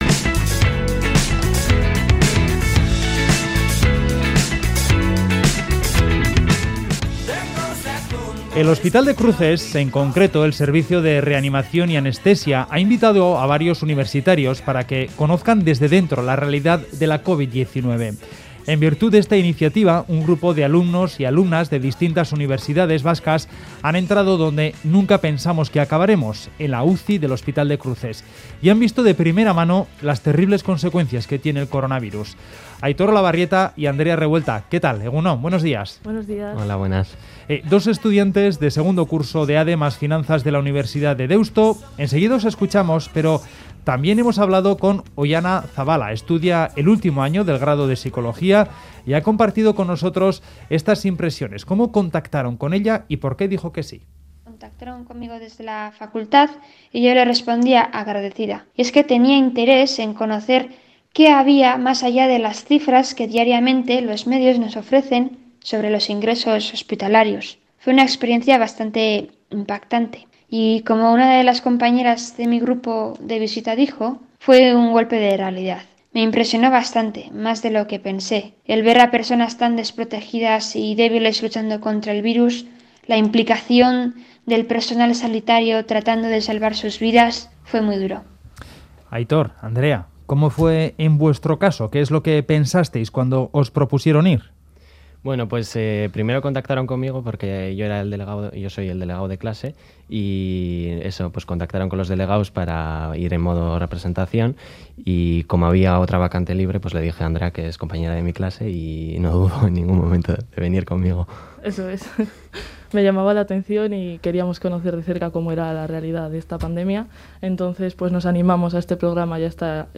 Audio: Estudiantes universitarios cuentan visita UCI COVID Hospital de Cruces